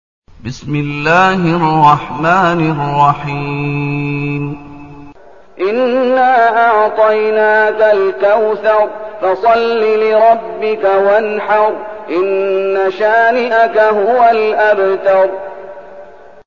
المكان: المسجد النبوي الشيخ: فضيلة الشيخ محمد أيوب فضيلة الشيخ محمد أيوب الكوثر